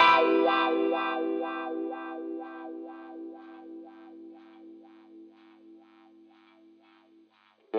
08 Wah Guitar PT2.wav